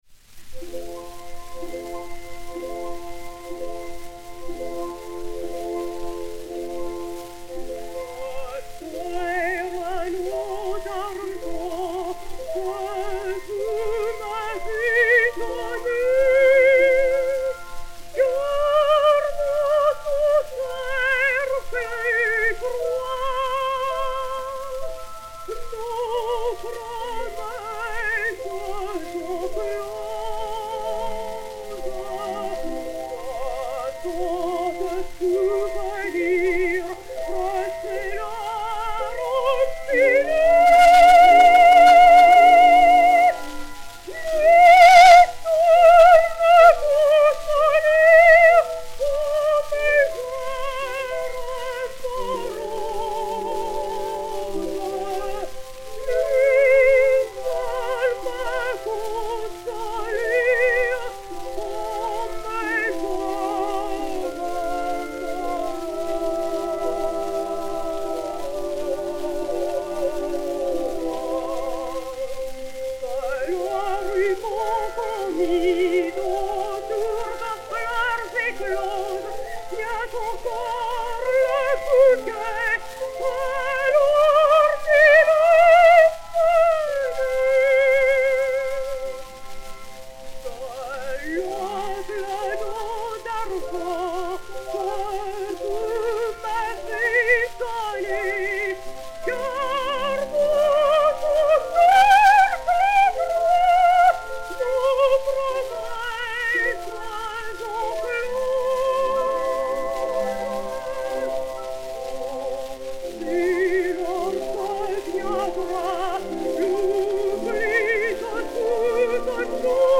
Yvonne Brothier (Cio-Cio-San), Suzanne Brohly (Souzouki) et Orchestre
CE78-1, enr. à Paris le 24 octobre 1921